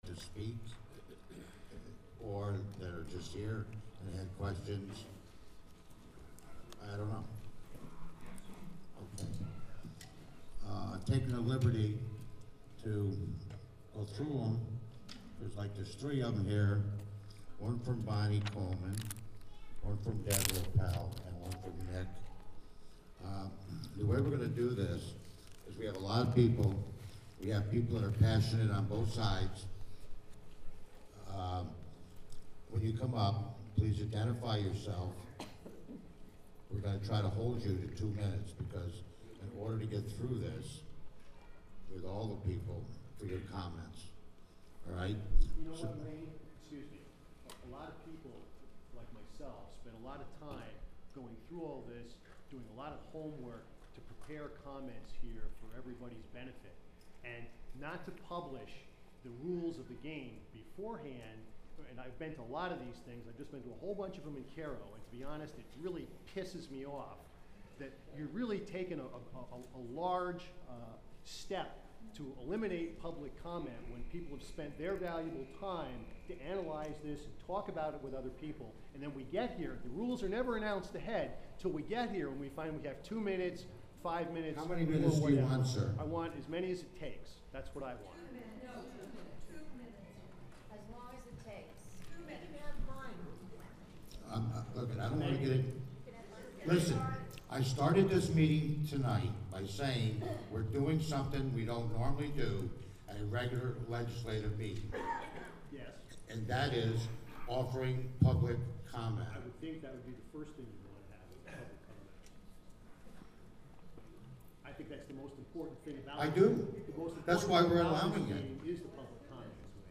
Public comments, and questions, at Greene IDA public meeting about proposed indoor water park in New Baltimore.
at Catskill High School.